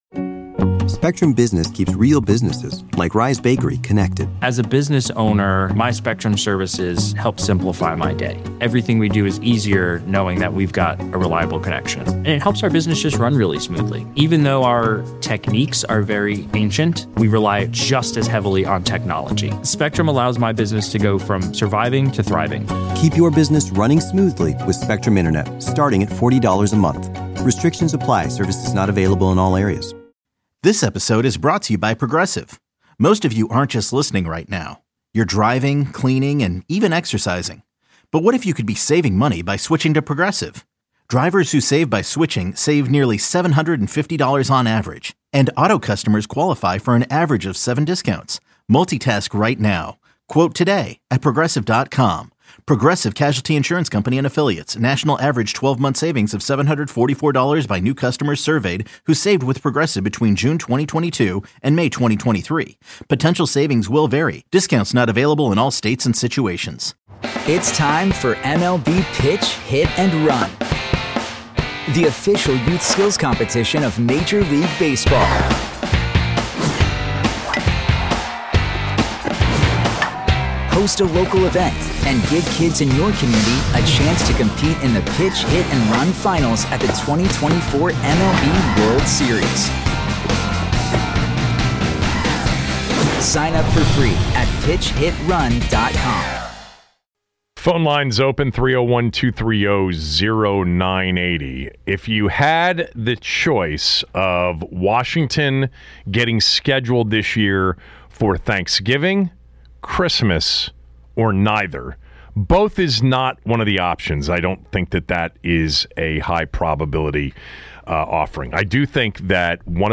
Callers give their thoughts on who they want the Commanders to play week 1 of the 2025 NFL season.